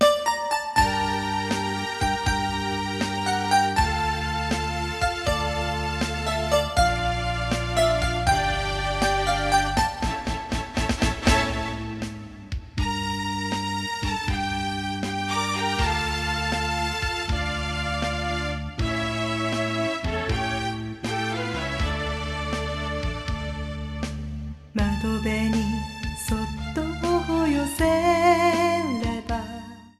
The source-quality rendition
Edited Trimmed to 30 seconds with added fadeout.